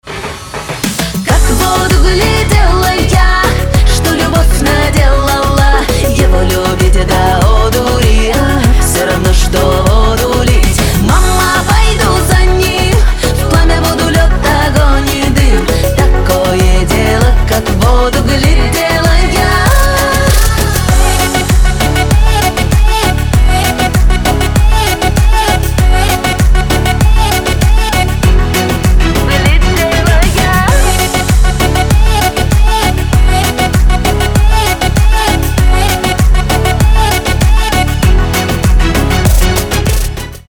• Качество: 320, Stereo
поп
женский вокал
зажигательные
dance